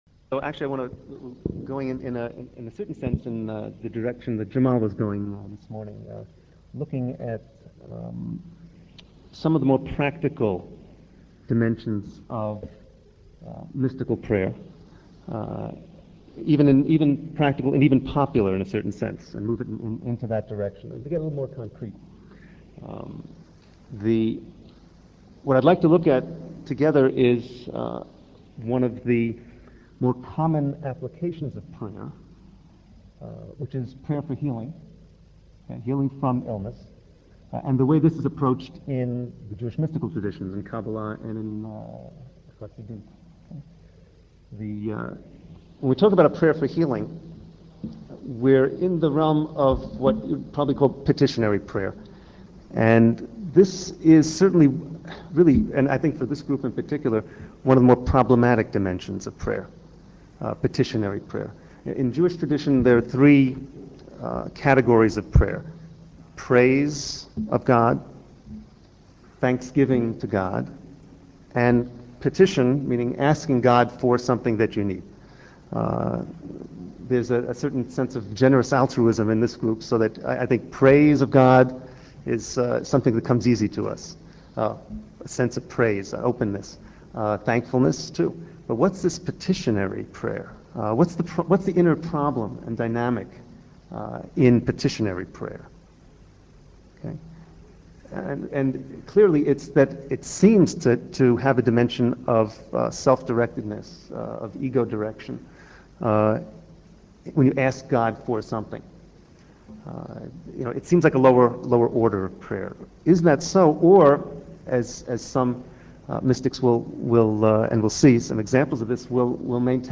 Audio Lectures on topics like interfaith alliance,interfaith christian,interfaith community,interfaith council,interfaith jewish